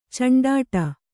♪ caṇḍāṭa